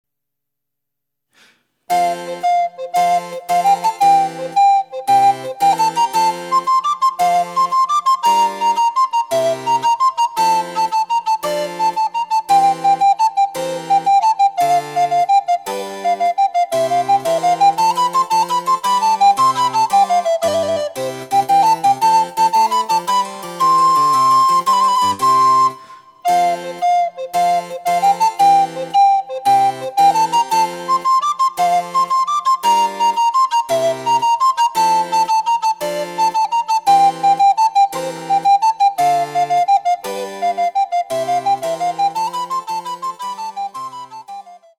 ■リコーダーによる演奏
リコーダー演奏
チェンバロ（電子楽器）演奏